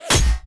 impact_projectile_metal_001.wav